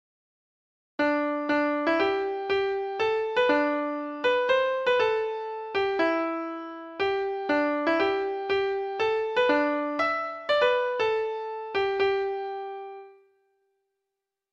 Folk Songs from 'Digital Tradition' Letter T The Five Carlins
Treble Clef Instrument  (View more Intermediate Treble Clef Instrument Music)
Traditional (View more Traditional Treble Clef Instrument Music)